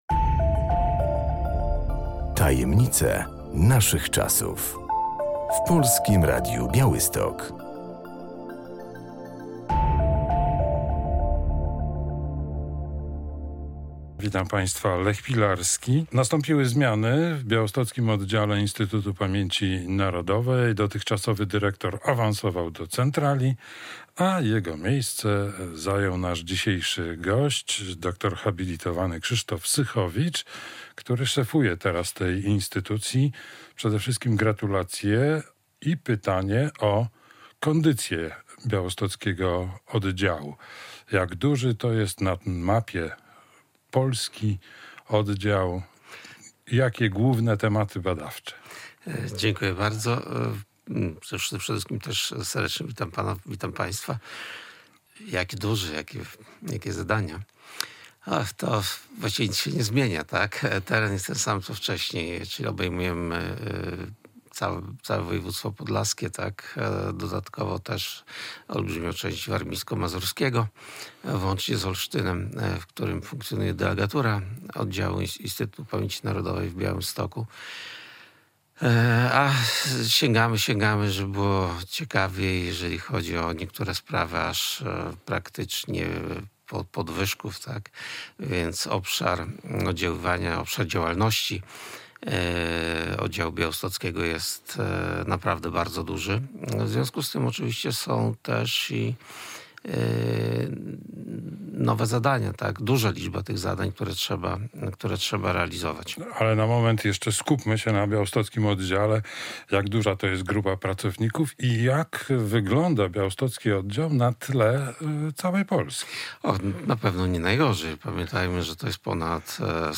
Słuchaj: Rozmowa z nowym dyrektorem białostockiego oddziału IPN dr hab. Krzysztofem Sychowiczem | Pobierz plik.